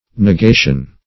Search Result for " nugation" : The Collaborative International Dictionary of English v.0.48: Nugation \Nu*ga"tion\, n. [Cf. OF. nugation.]